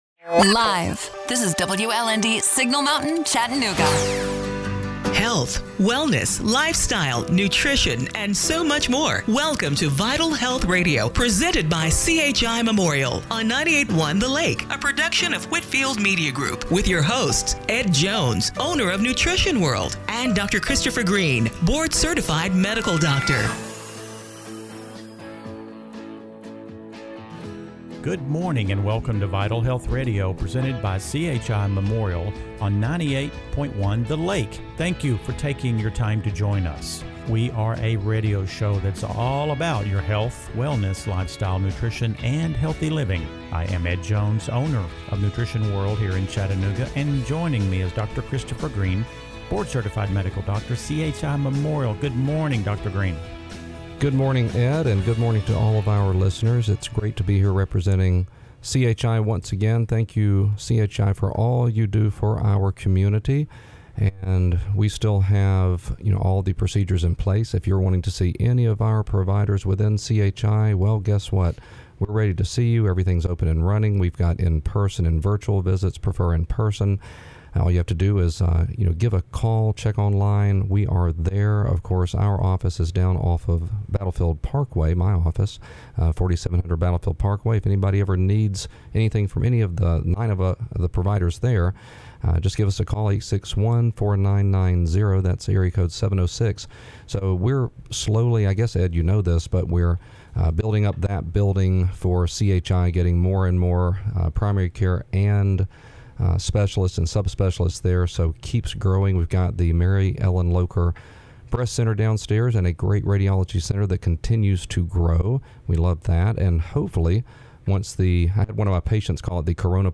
September 13, 2020 – Radio Show - Vital Health Radio